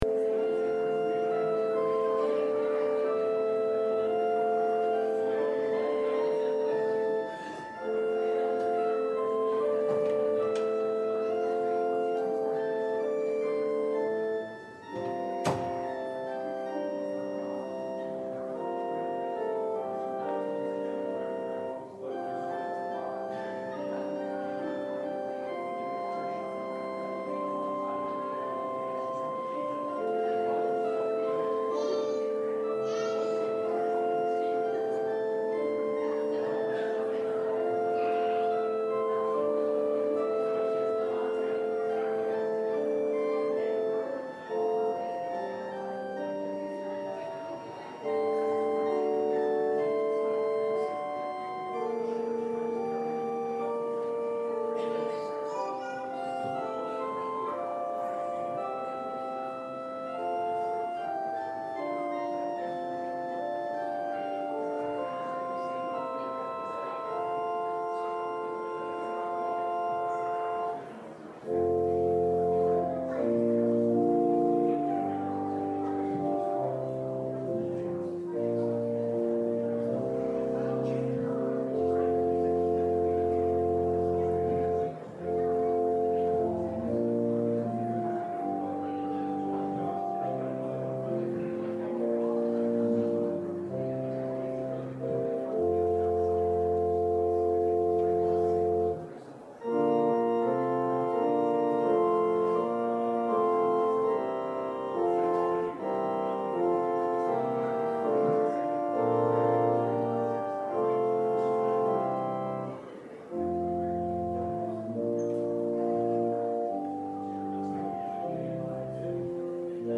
Romans 12 Service Type: Sunday Worship Topics